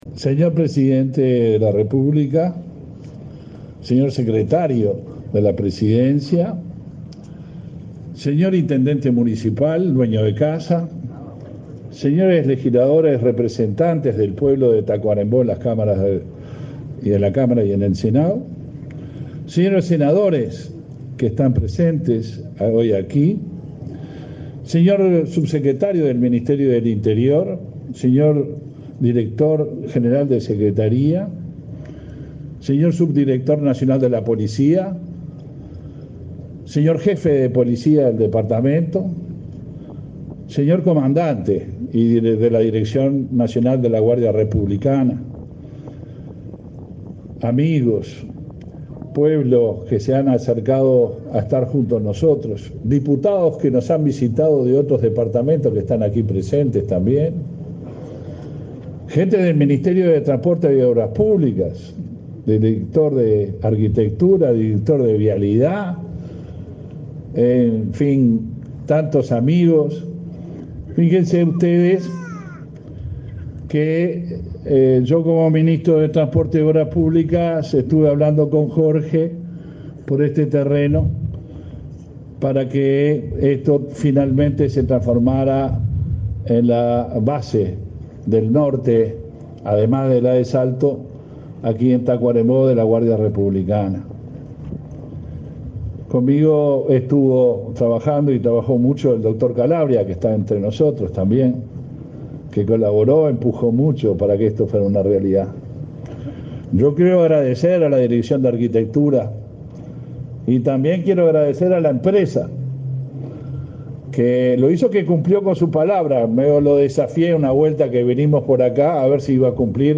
Palabras del ministro del Interior, Luis Alberto Heber
Palabras del ministro del Interior, Luis Alberto Heber 19/05/2023 Compartir Facebook X Copiar enlace WhatsApp LinkedIn Con la presencia del presidente de la República, Luis Lacalle Pou, se realizó, este 19 de mayo, el acto de la base de la Guardia Republicana que funcionará en Tacuarembó bajo el nombre Dr. Jorge Larrañaga. En el evento el ministro del Interior, Luis Alberto Heber, realizó declaraciones.